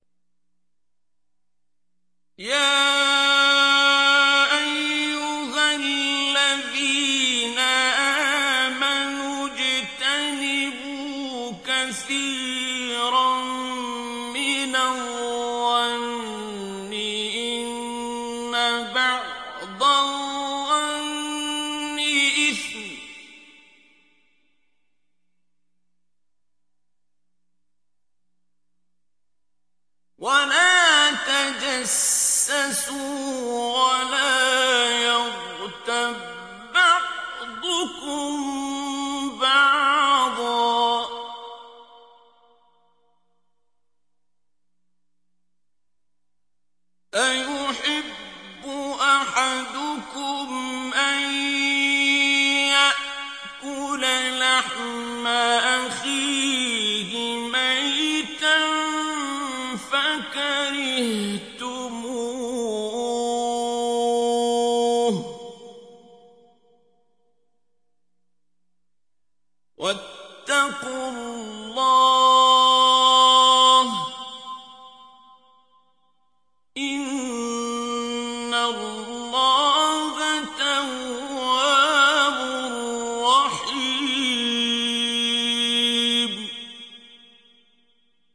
تلاوت آیه 12 سوره حجرات توسط استاد عبدالباسط